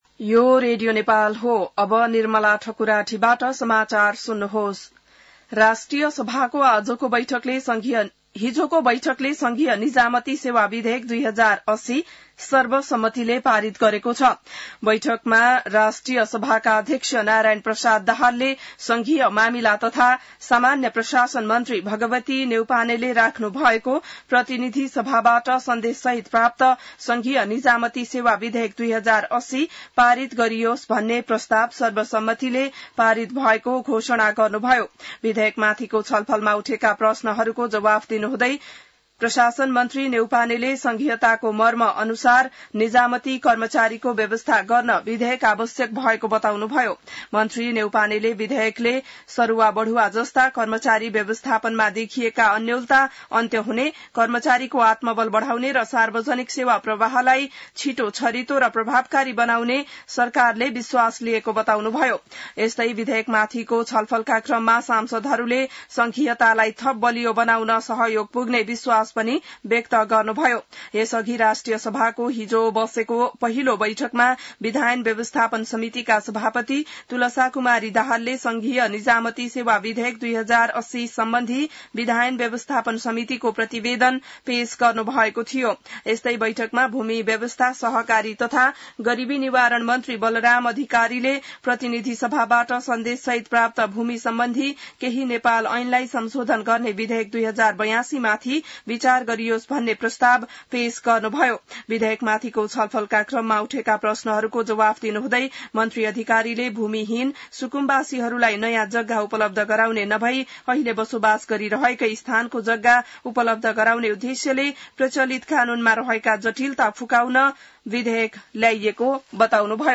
An online outlet of Nepal's national radio broadcaster
बिहान ६ बजेको नेपाली समाचार : १८ भदौ , २०८२